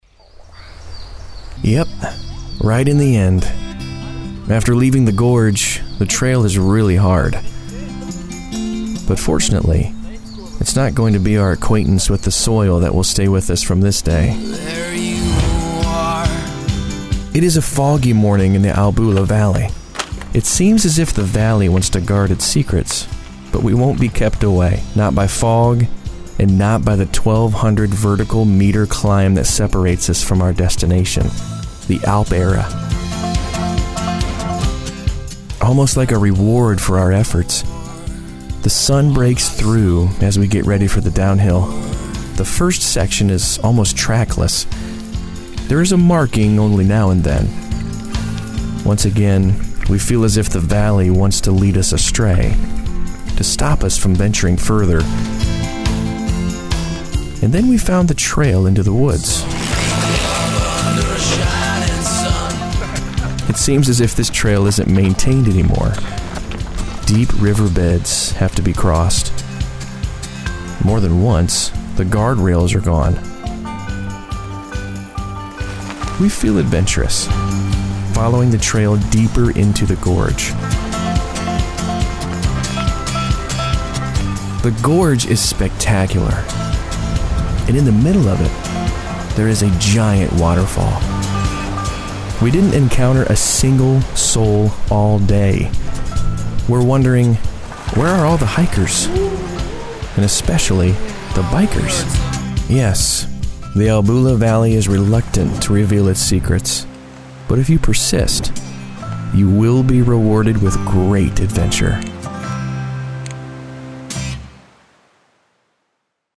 Male
Yng Adult (18-29), Adult (30-50)
I have a conversational, natural and Everyman type of voice.
From sincere with a hint of gravitas to excitable and young 20's sound.
Documentary
First Person Documentary
Words that describe my voice are Conversational, Natural, Everyman.